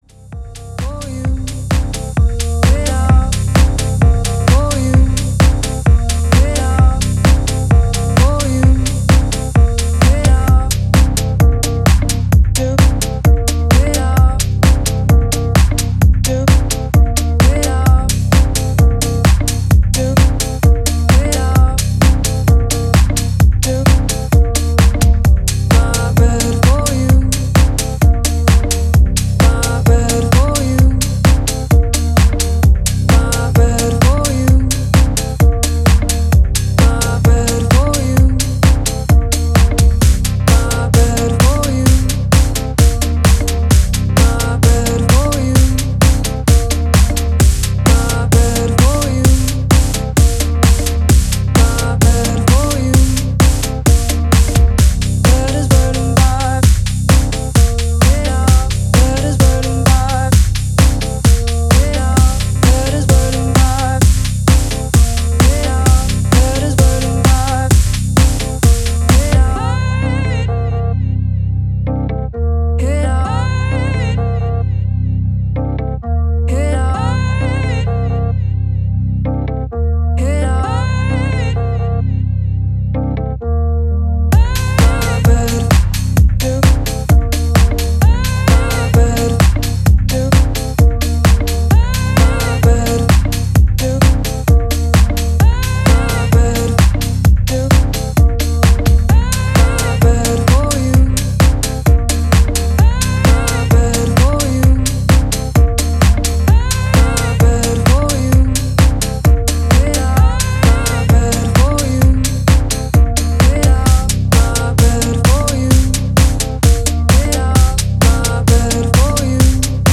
AOR的ネタ使いのディスコティック・ミニマル